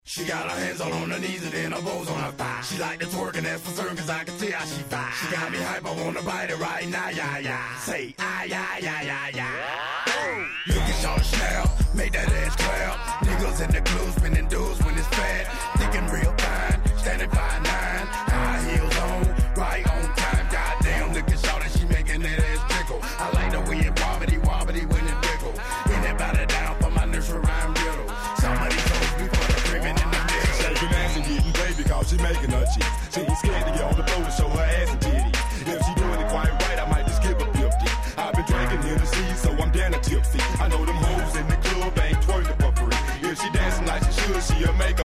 01' Big Hit Hip Hop !!